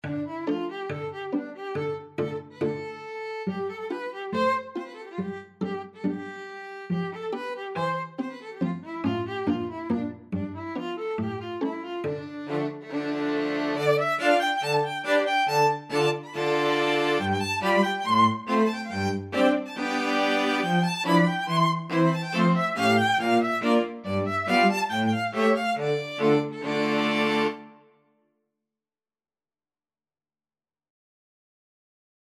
String Quartet version
Violin 1Violin 2ViolaCello
= 140 Allegro (View more music marked Allegro)
2/4 (View more 2/4 Music)
Classical (View more Classical String Quartet Music)